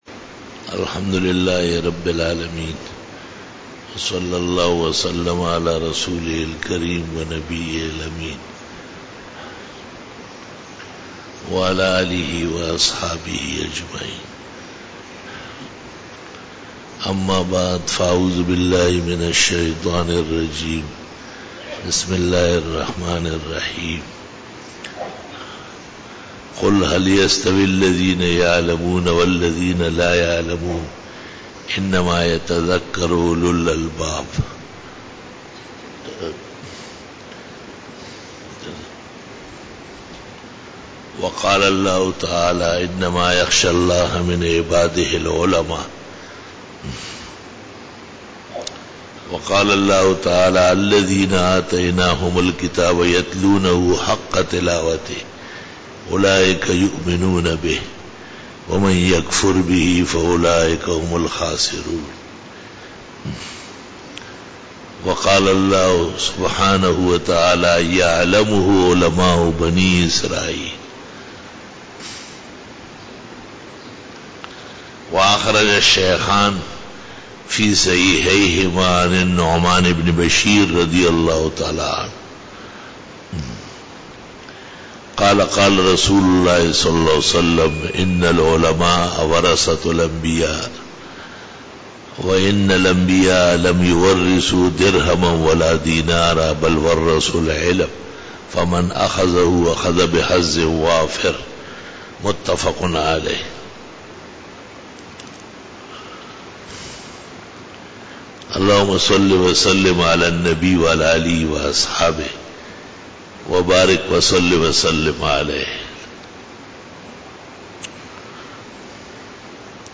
20_BAYAN E JUMA TUL MUBARAK 15-MAY-2014
بیان جمعۃ المبارک 15 مئی 2014